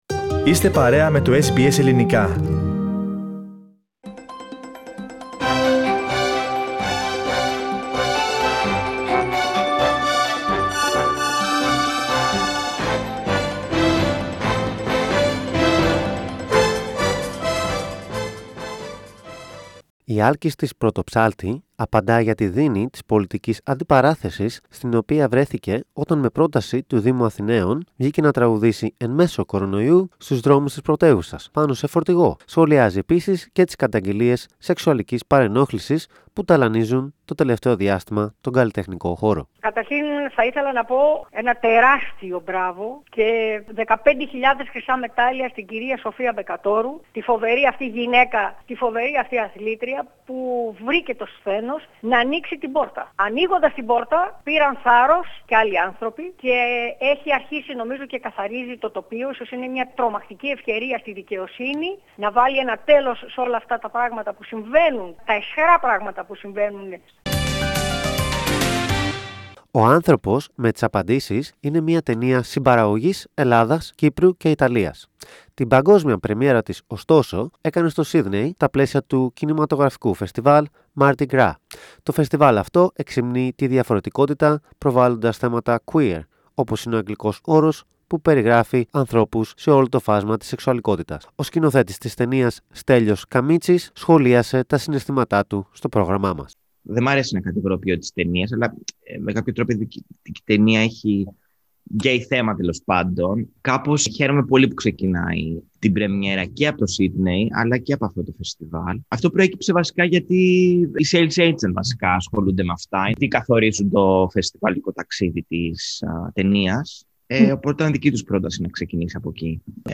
Συνέντευξη με την Άλκηστις Πρωτοψάλτη , πρώτη προβολή ταινίας Έλληνα σκηνοθέτη με ομοφυλοφιλική θεματική, διαδικτυακές δραστηριότητες για τον απόδημο Ελληνισμό, τα κρατικά εργαλεία εντοπισμού και αναζήτησης κρουσμάτων COVID-19, και θετικά μηνύματα για τον εμβολιασμό, ήταν μερικά από τα θέματα που μας απασχόλησαν την περασμένη εβδομάδα.
Main Greek Highlights of the Week Source: SBS Radio Greek Program